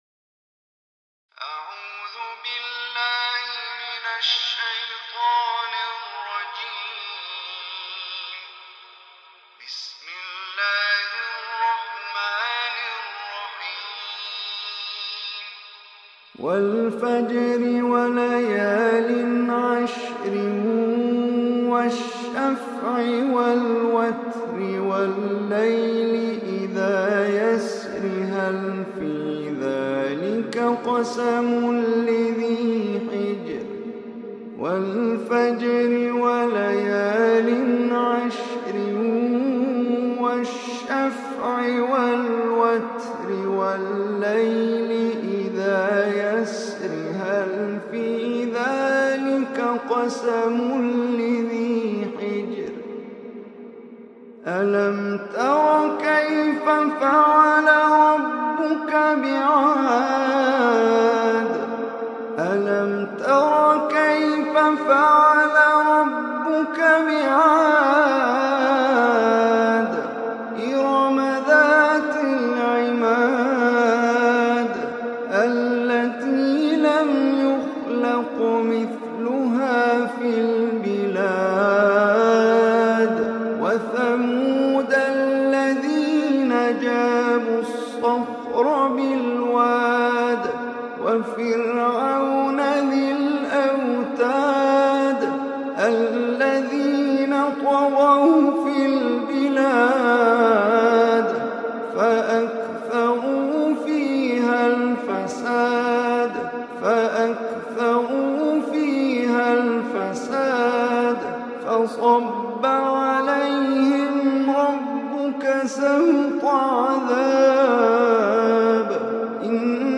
Surah Fajr, is 89 surah of Quran. Listen or play online mp3 tilawat / recitation in Arabic in the beautiful voice of Omar Hisham Al Arabi.